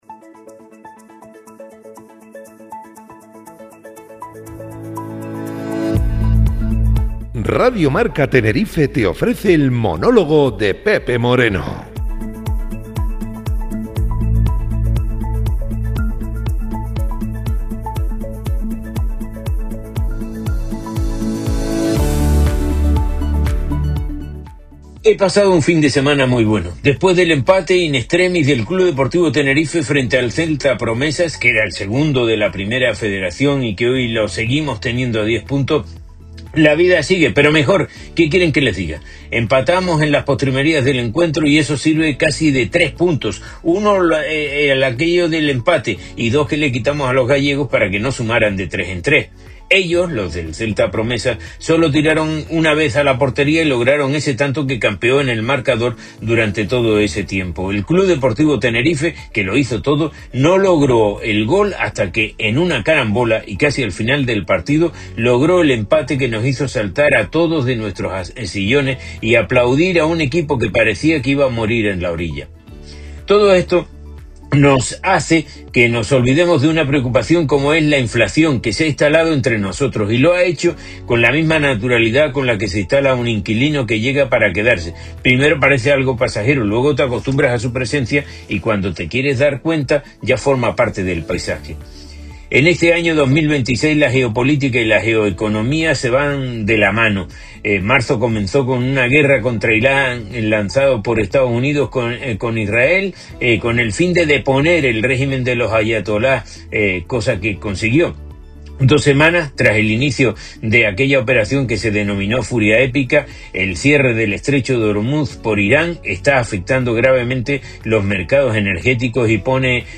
El Monólogo